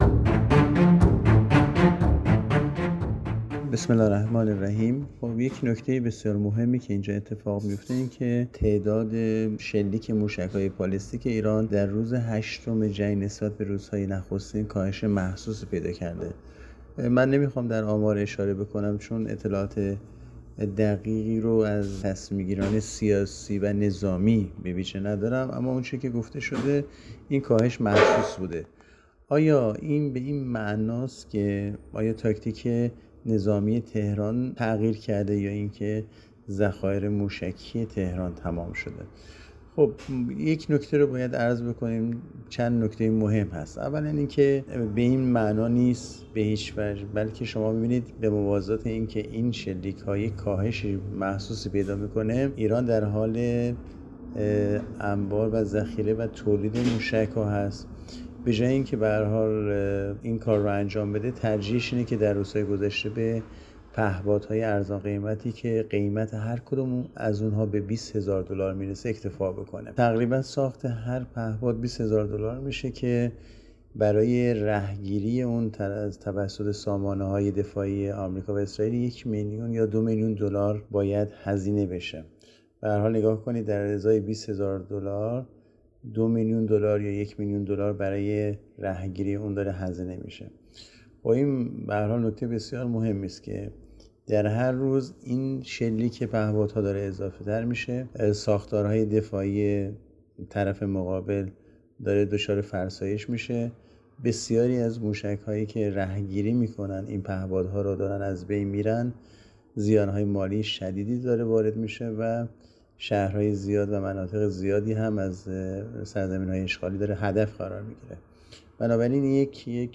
نویسنده و گوینده